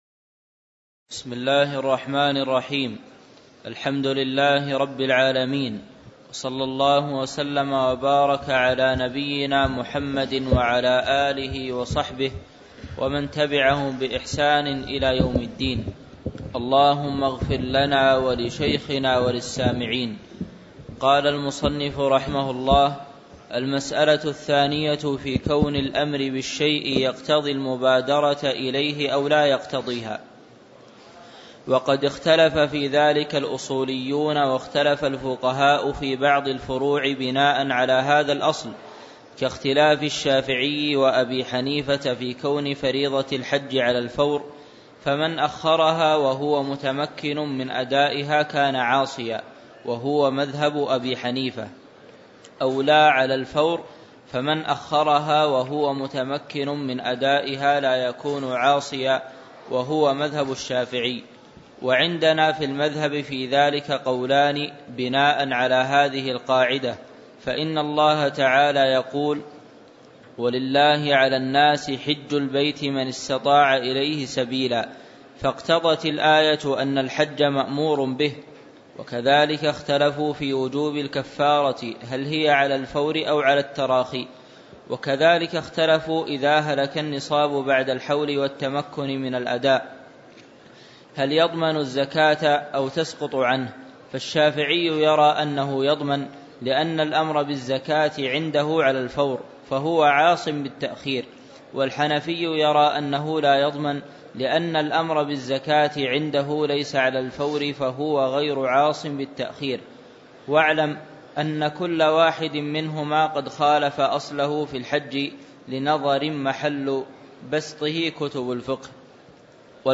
تاريخ النشر ١٣ جمادى الأولى ١٤٤١ هـ المكان: المسجد النبوي الشيخ